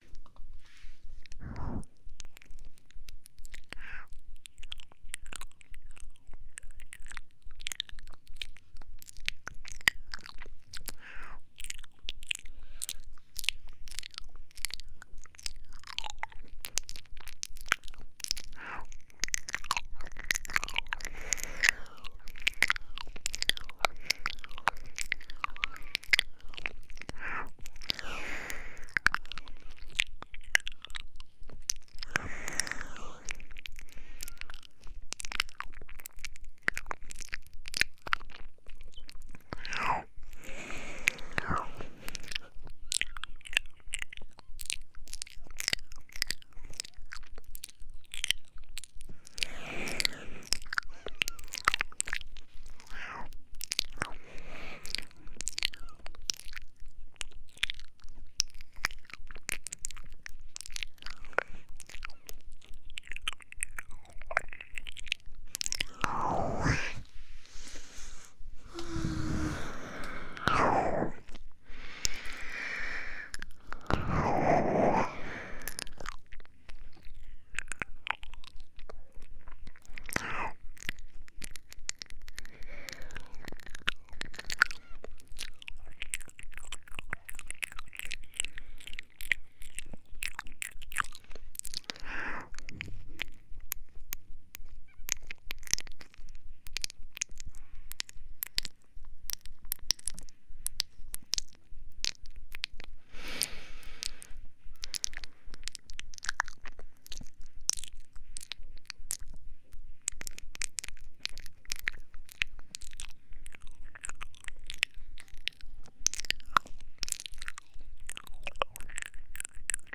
酥酥麻麻的双耳口腔音喵喵喵.mp3